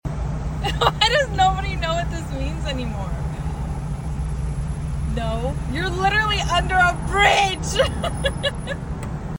This rev fail bothers me sound effects free download